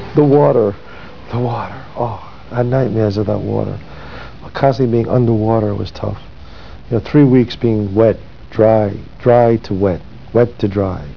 Water - (Chazz talks about the water during filming of Diabolique) 120KB